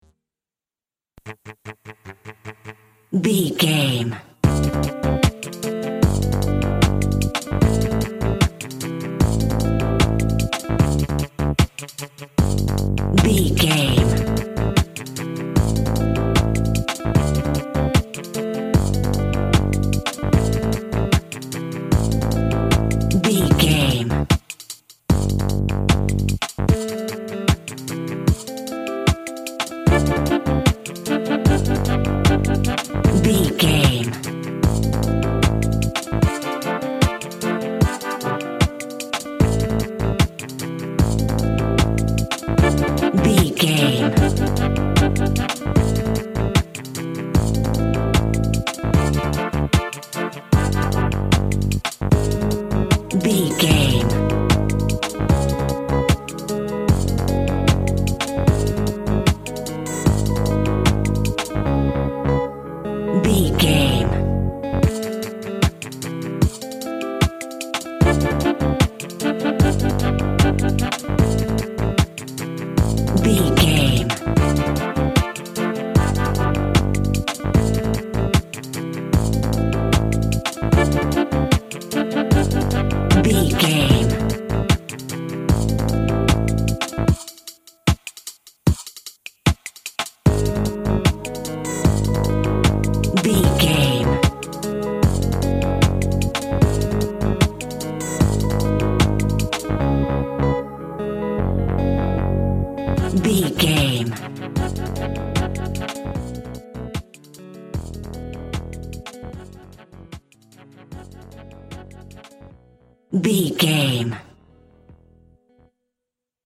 Also with small elements of Dub and Rasta music.
Aeolian/Minor
drums
bass
guitar
piano
brass
steel drum